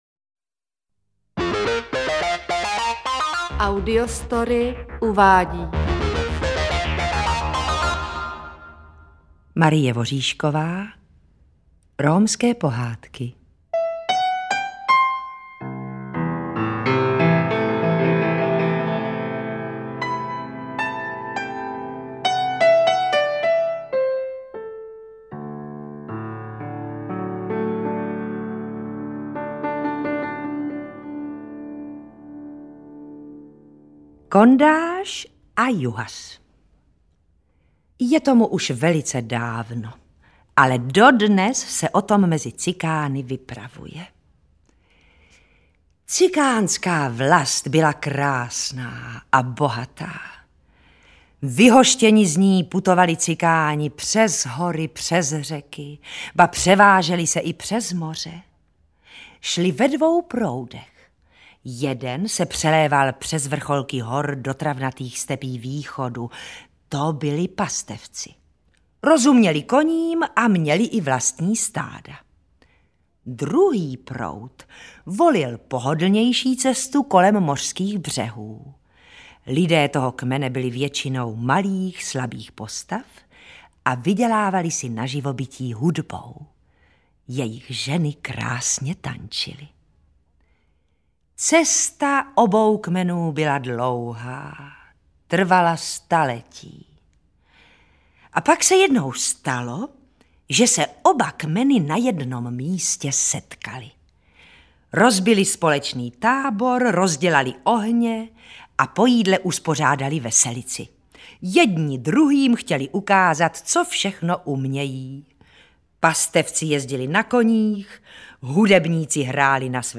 Interpret:  Gabriela Vránová
AudioKniha ke stažení, 3 x mp3, délka 1 hod. 14 min., velikost 169,5 MB, česky